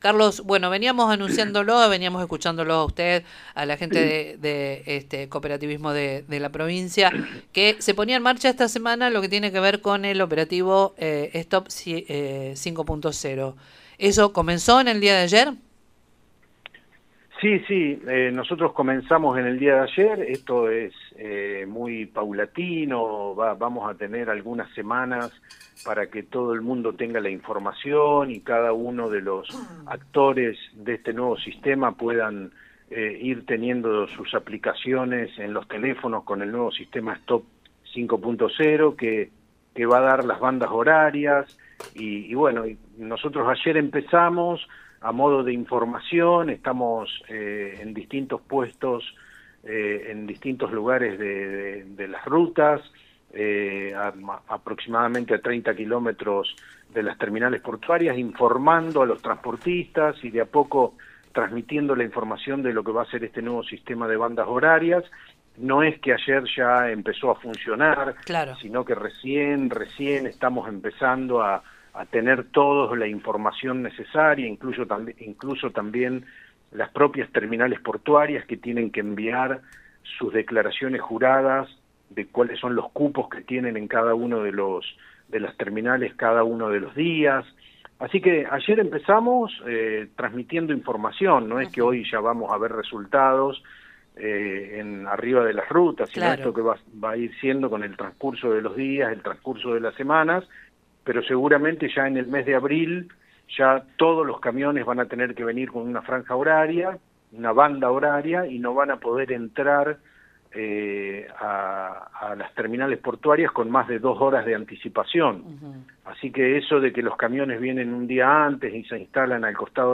El secretario de la Agencia Provincial de Seguridad Vial, Carlos Torres, se refirió al inicio del operativo Stop 5.0, un sistema que busca ordenar el ingreso de camiones a los puertos mediante la asignación de turnos.
En diálogo con el programa Con Voz de Radio Nueva Estrella 102.9, explicó que desde el lunes 16 de marzo comenzaron a realizarse ajustes en la logística virtual, con el objetivo de que transportistas y empresas se adapten de manera progresiva a la nueva modalidad.